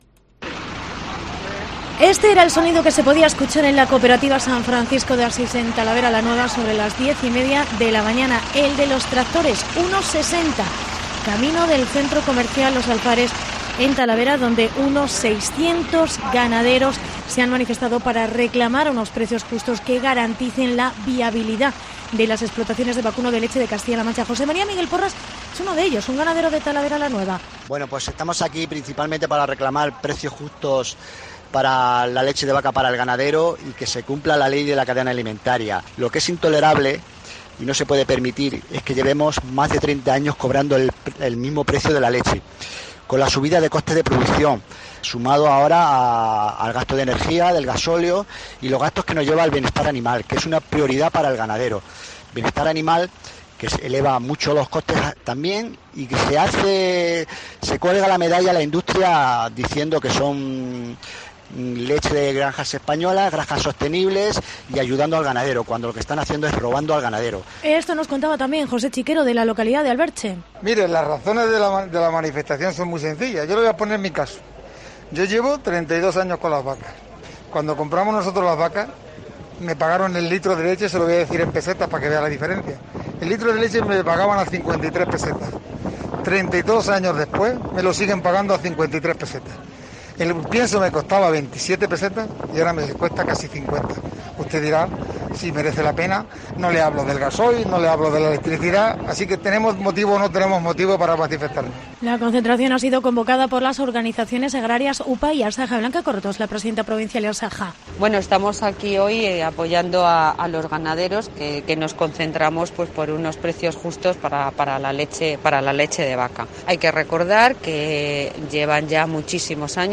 Reportaje manifestación ganaderos de leche en Talavera